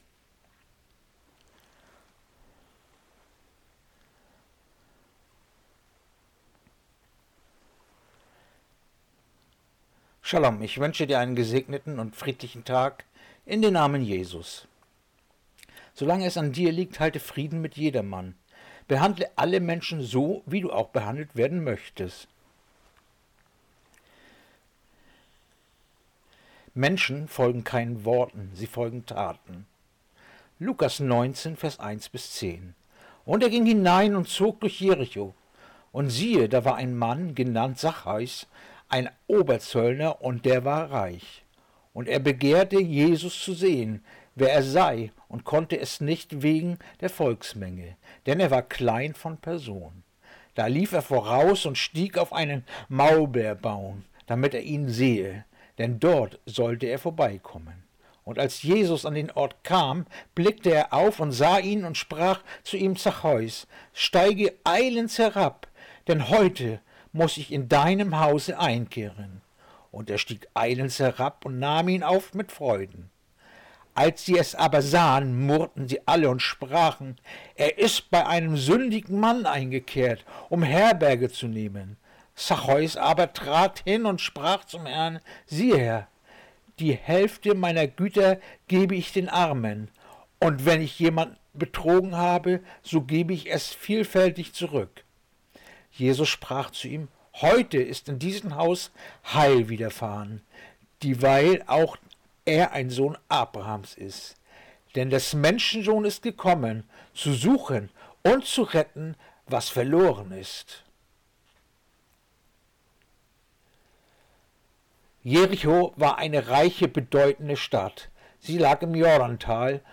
Andacht-vom-09.-November-Lukas-19-1-10